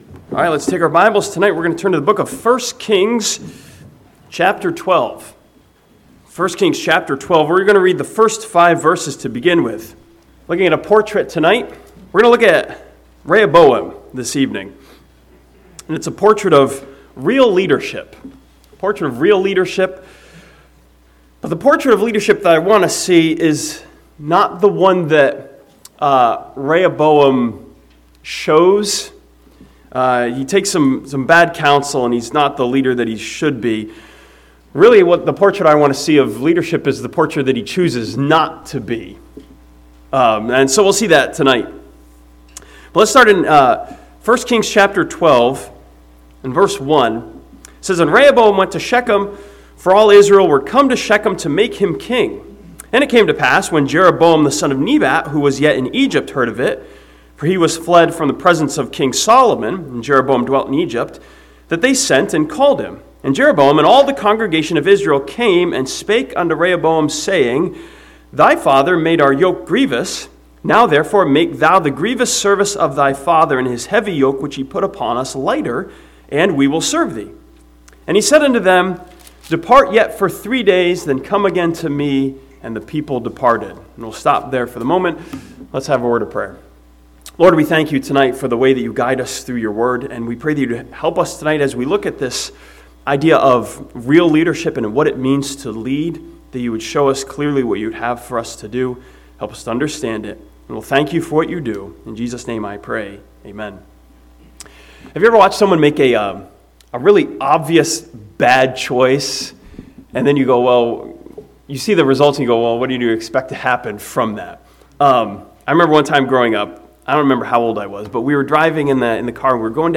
This sermon from 1 Kings chapter 12 challenges us with a portrait of real leadership, teaching us that leaders serve and servants lead.